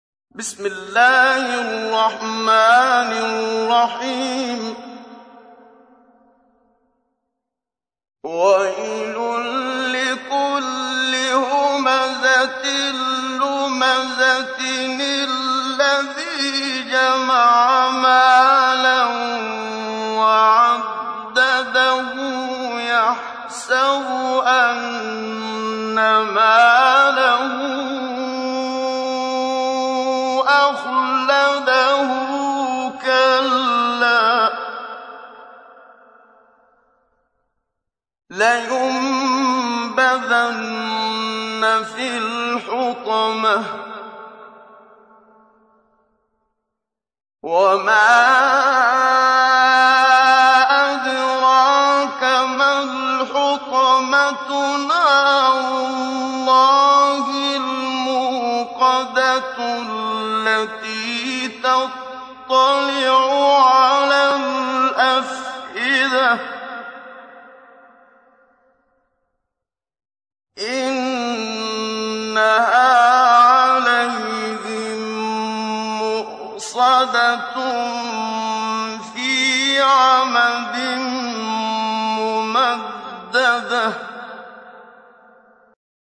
تحميل : 104. سورة الهمزة / القارئ محمد صديق المنشاوي / القرآن الكريم / موقع يا حسين